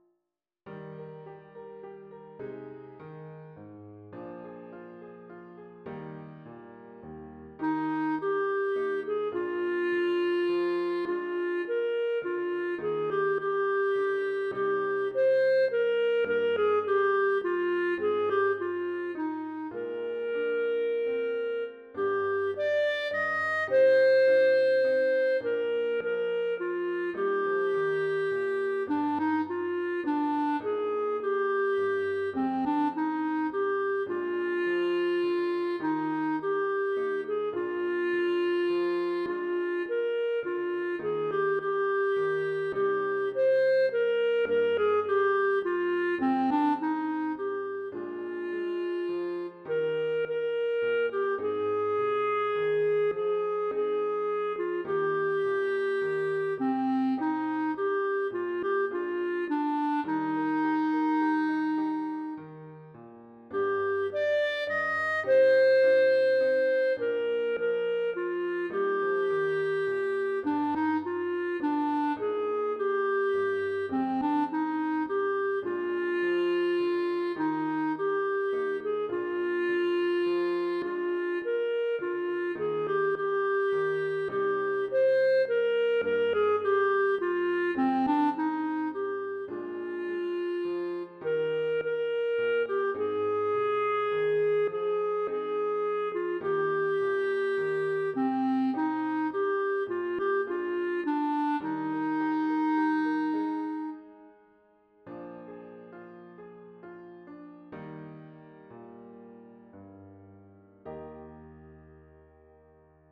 Voicing: Bb Clarinet and Piano